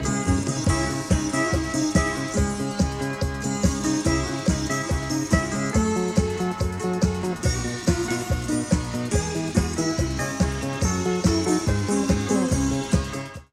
Сегодня прослушивая старые кассеты, на кассете с "Аэробикой", обратил внимания на неизвестную мелодию.
Кусочек в 13сек (где нет слов ведущей) прилагается.